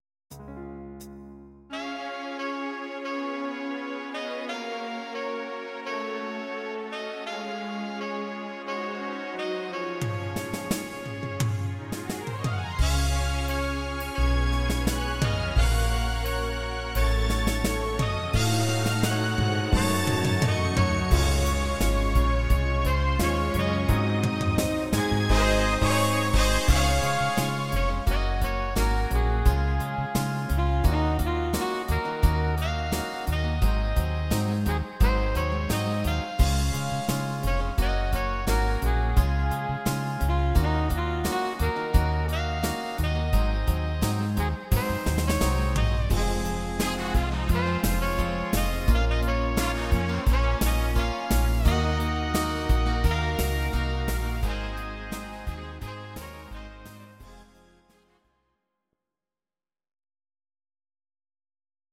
These are MP3 versions of our MIDI file catalogue.
Please note: no vocals and no karaoke included.
Your-Mix: 1960s (3135)